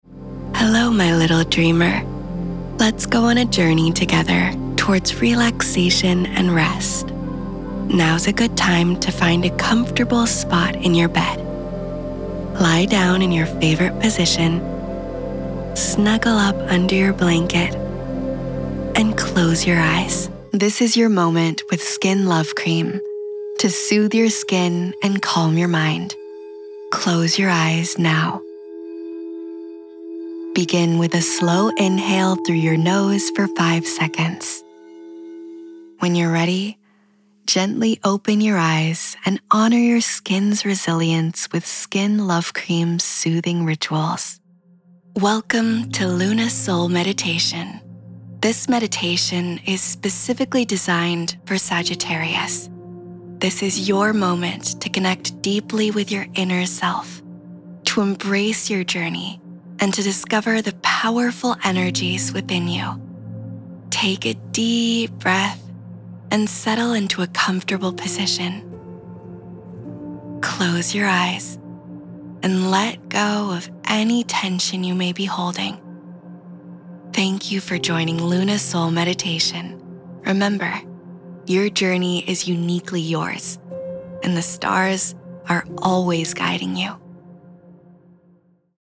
A young, fresh, feminine and soft voice with genuine warmth and clarity
Guided Meditation Reel